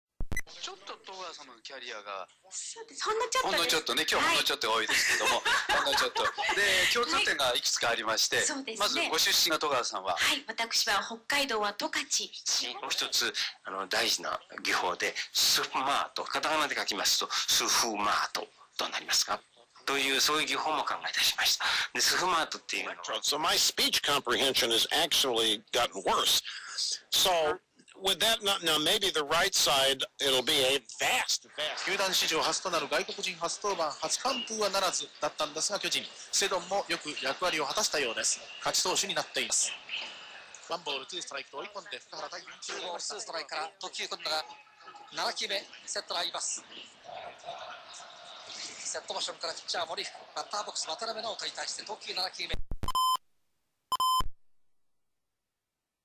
こちらは、自宅での受信音声ファイルはこちら。
アンテナは15m高のスモール・アンテナを使用しています。
NHK第一、第二、AFN、TBS、文化放送の順に同調操作をしています。
この録音ファイルは音量レベルを下げる以外の加工はしていません。
記事で製作したラジオの音声出力をそのまま使用しています。　オーディオ・アンプ無しでも、十分に聞こえます。
安いICレコーダーを使用したので、ハイ・ローの感度切り替えしか無く聴きやすい音量になる様にボリュームで調整しています。
mosfet_radio_jitaku.wma